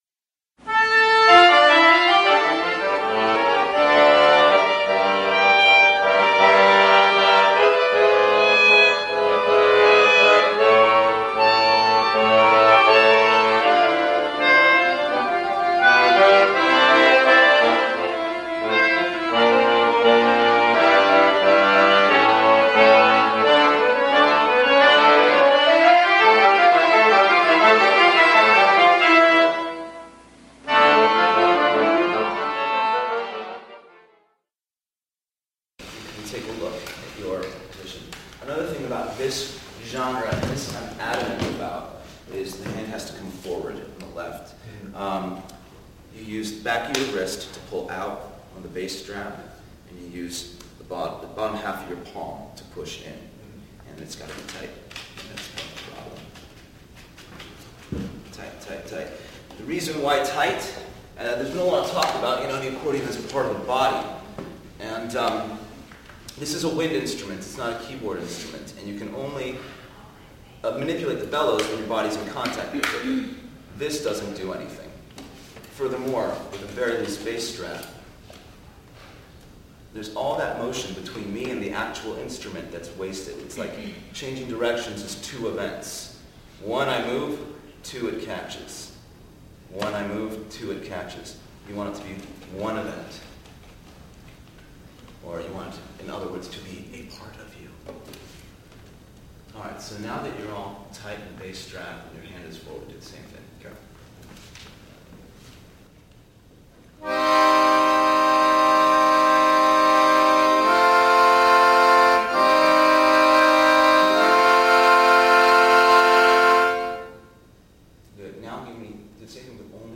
rock and roll accordion piece